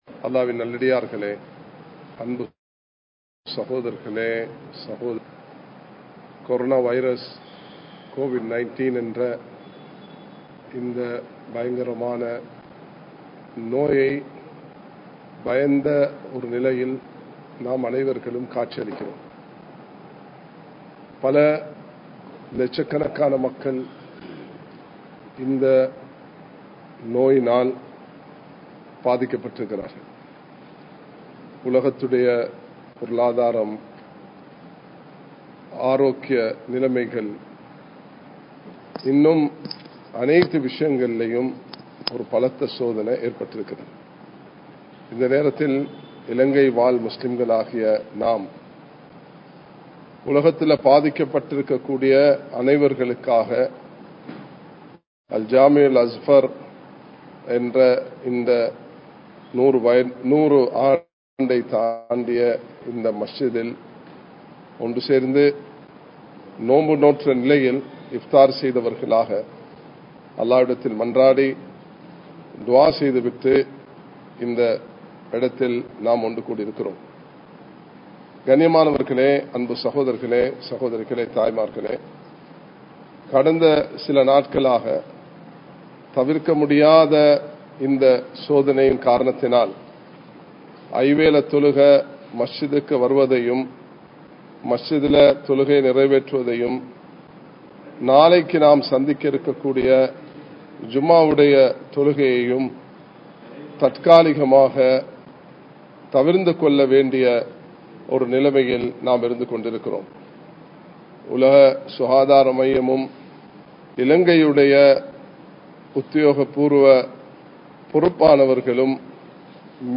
Bayans
Colombo 11, Samman Kottu Jumua Masjith (Red Masjith)